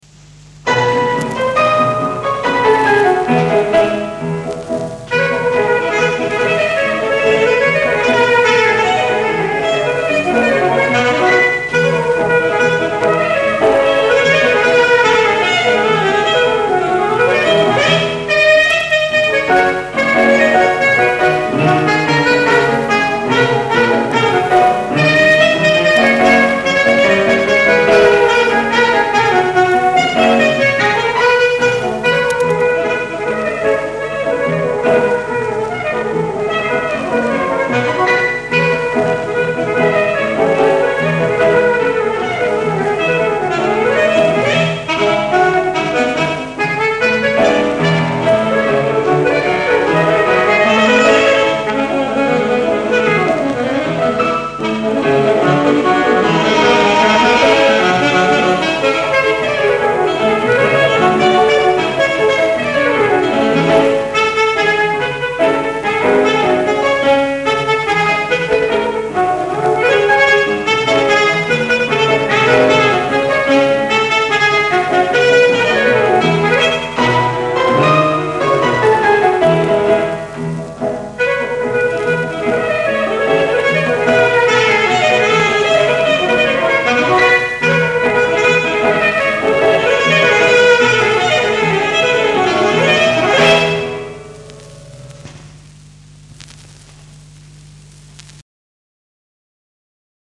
Variété./4